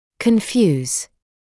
[kən’fjuːz][кэн’фйуːз]смешивать, спутывать; запутывать, сбивать с толку, приводить в замешательство